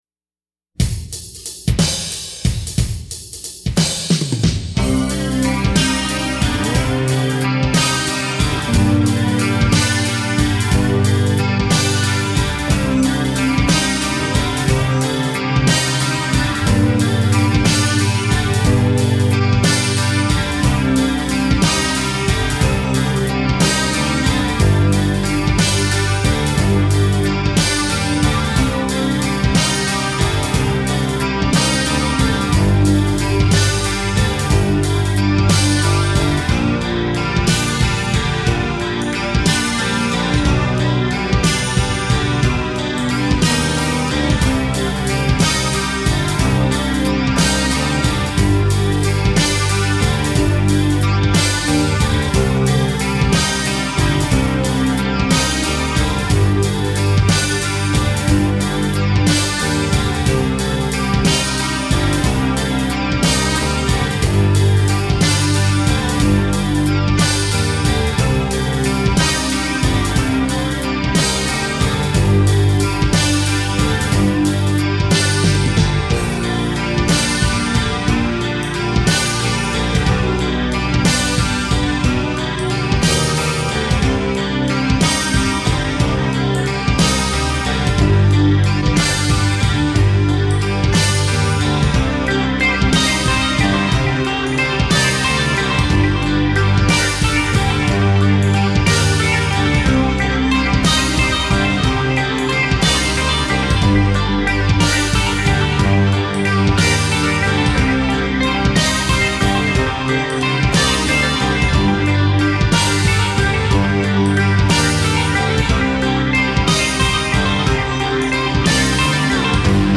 Rhythm Guitars, Programming
Lead and Rhythm Guitars
Bass